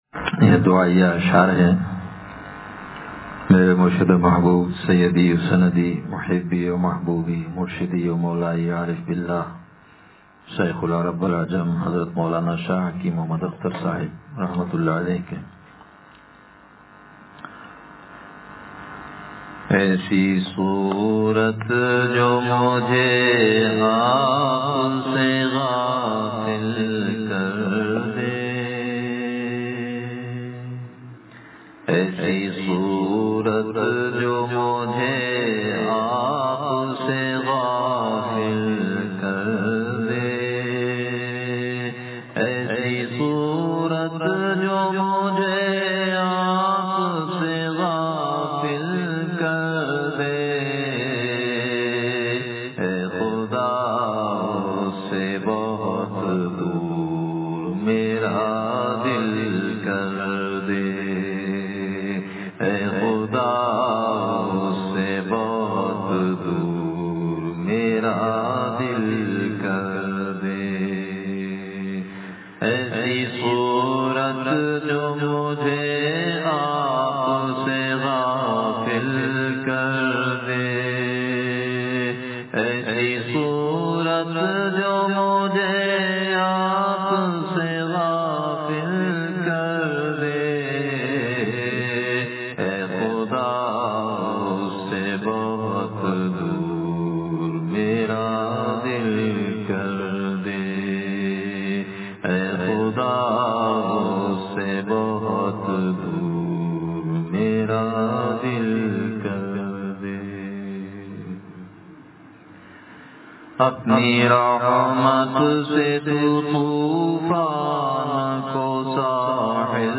تراویح میں ختمِ قرآنِ کریم کے موقع پر بیان – مجالسِ ابرار- دنیا کی حقیقت – نشر الطیب فی ذکر النبی الحبیب صلی اللہ علیہ وسلم